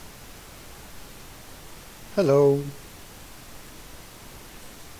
I get a lot of noise when recording audio via a microphone in headset plugged into the 2.5mm jack.
) is done with the mic plugged in and me saying “hello”.
In both cases the gain is set to 45% in pavucontrol.
withmic.ogg